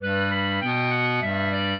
clarinet
minuet2-7.wav